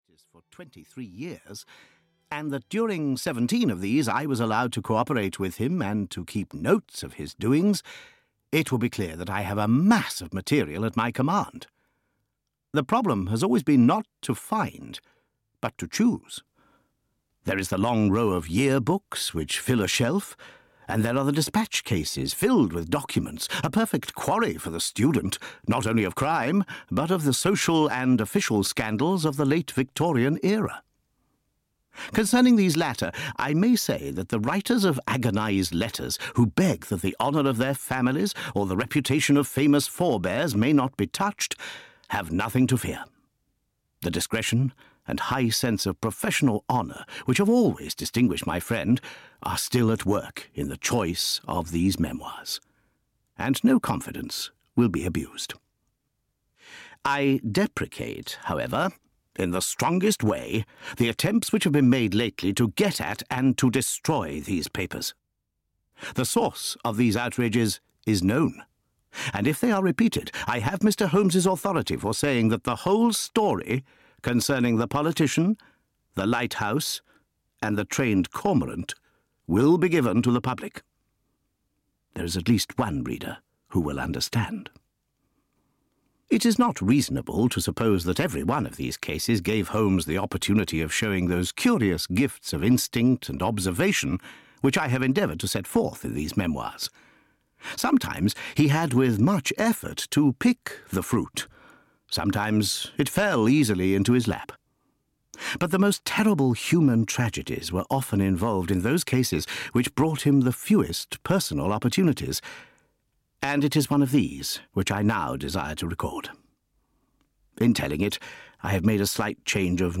The Casebook of Sherlock Holmes II (EN) audiokniha
Ukázka z knihy